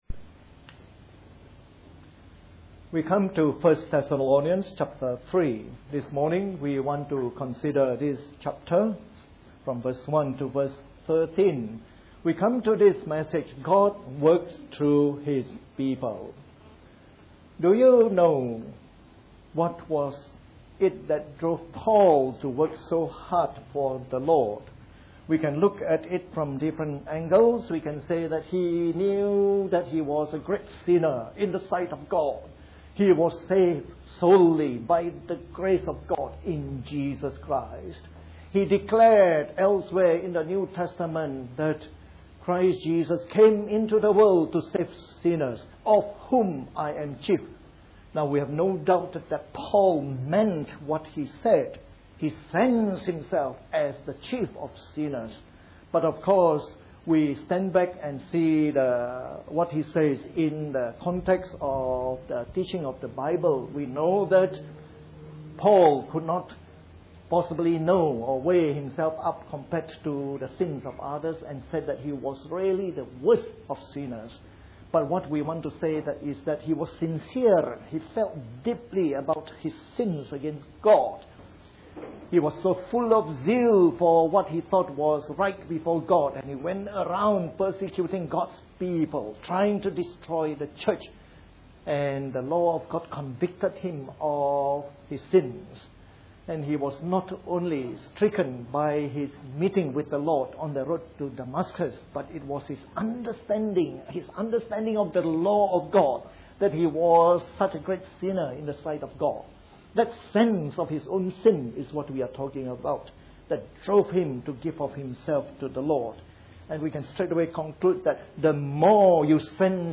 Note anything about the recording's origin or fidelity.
A sermon in the morning service from our series on 1 Thessalonians.